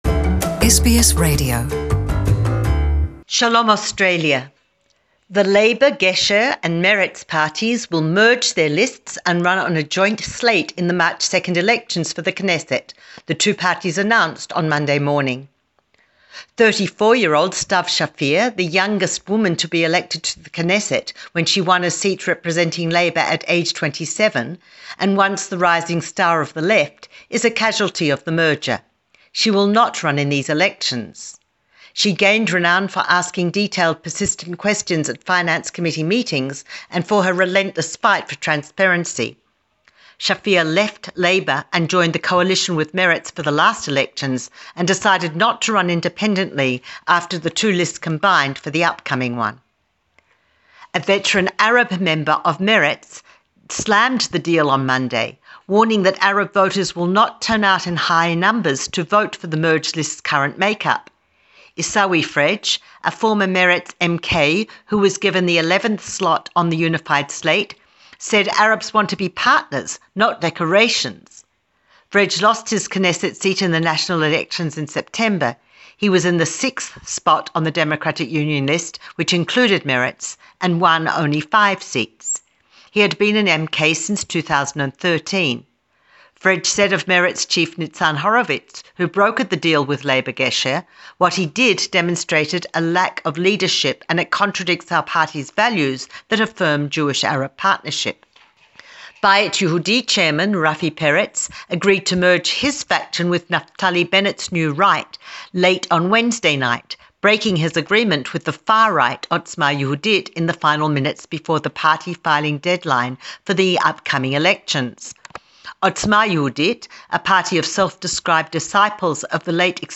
A weekly news update from Israel, in English.